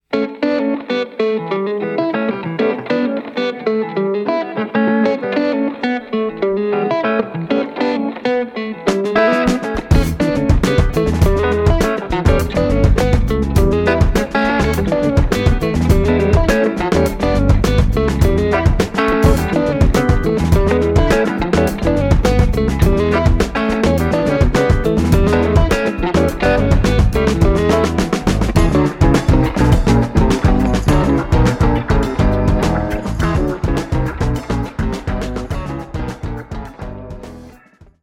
wurlitzer, piano